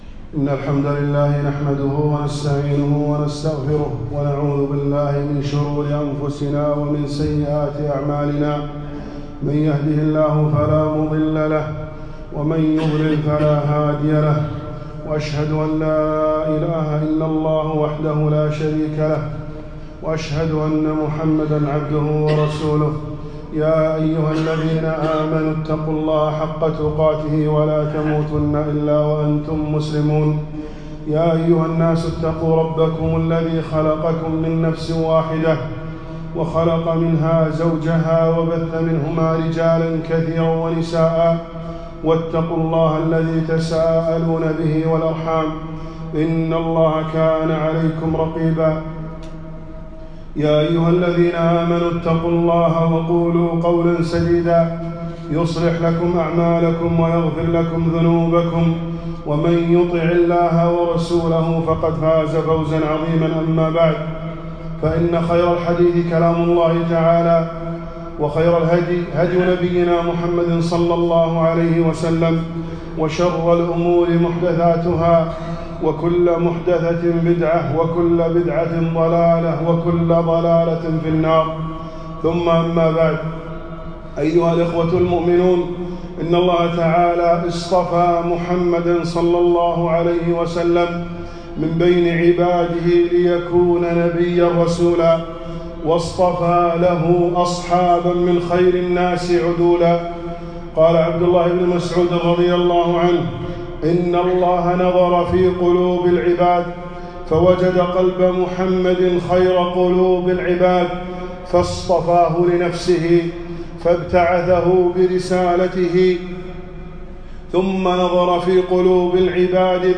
خطبة - أمهاتُ المؤمنين